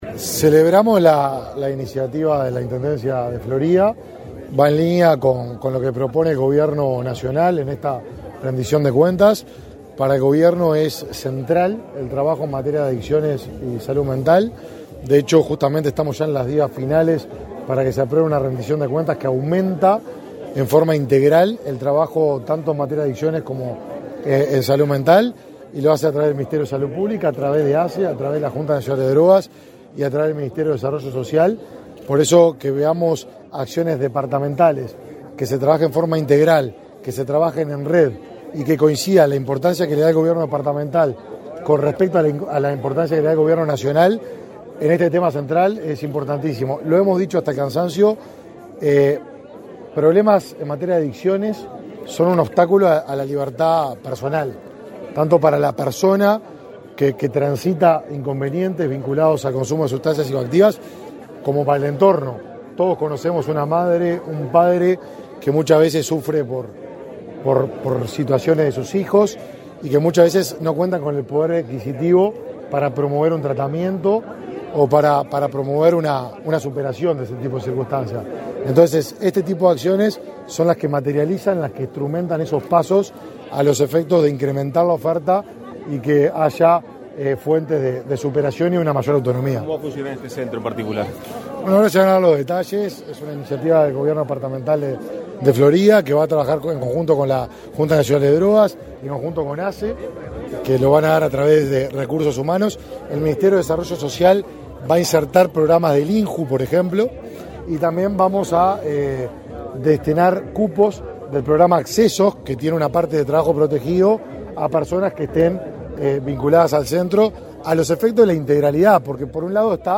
Declaraciones a la prensa del ministro del Mides, Martín Lema
Declaraciones a la prensa del ministro del Mides, Martín Lema 27/09/2023 Compartir Facebook X Copiar enlace WhatsApp LinkedIn En el marco de la inauguración del centro diurno para personas que padecen consumo problemático de drogas, en Florida este 27 de setiembre, el titular del Ministerio de Desarrollo Social, Martín Lema, realizó declaraciones a la prensa.